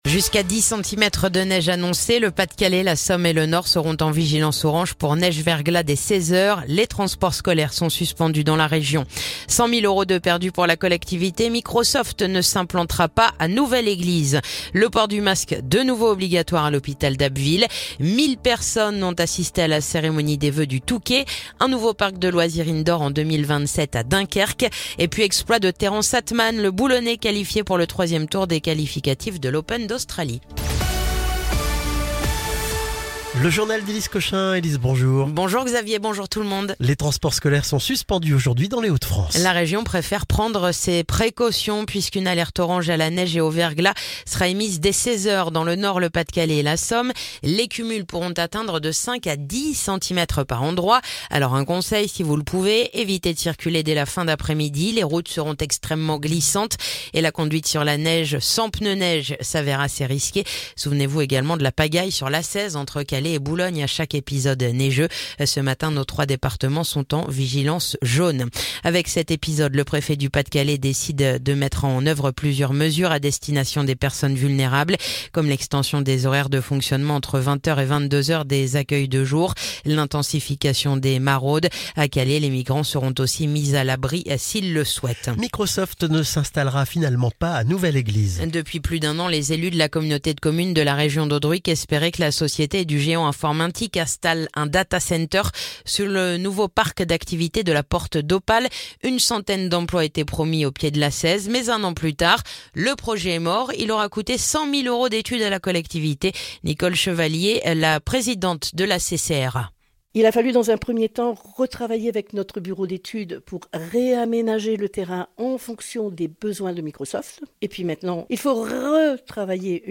Le journal du mercredi 8 janvier